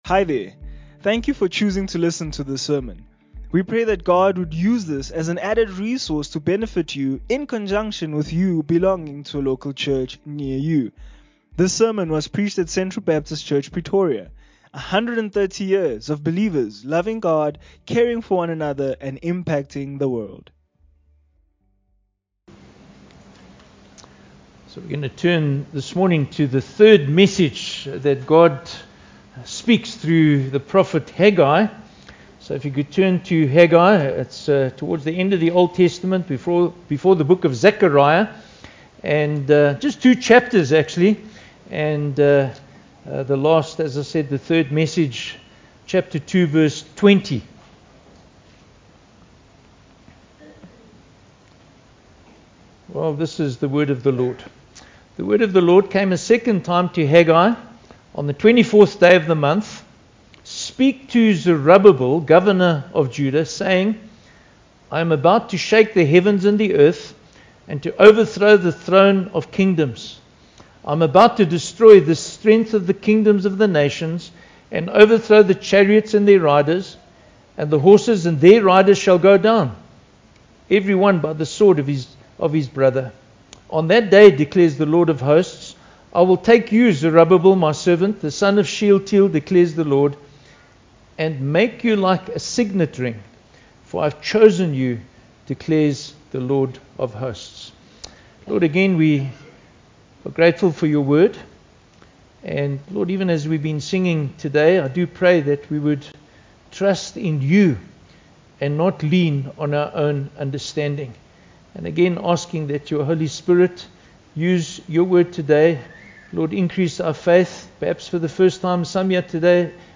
Sermons - Central Baptist Church Pretoria